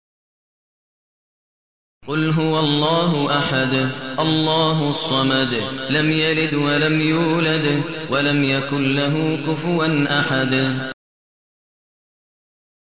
المكان: المسجد الحرام الشيخ: فضيلة الشيخ ماهر المعيقلي فضيلة الشيخ ماهر المعيقلي الإخلاص